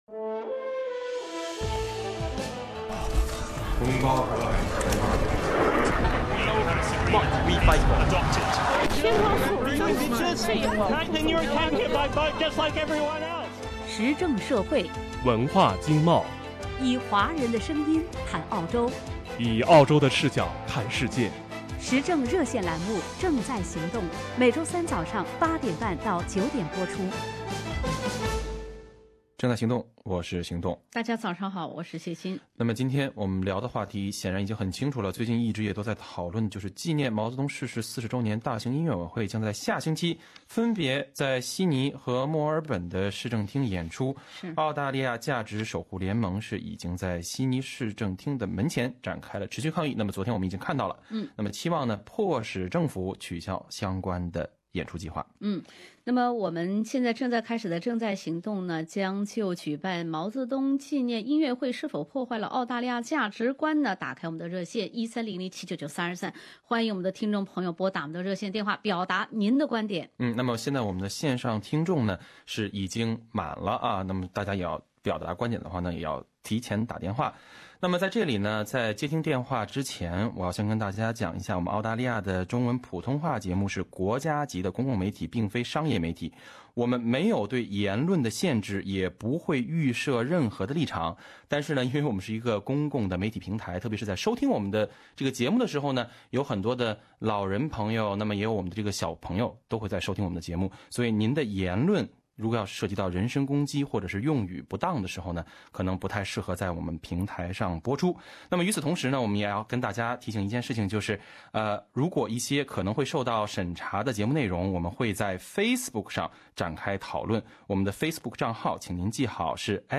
《正在行动》节目听众拨打热线表达自己观点。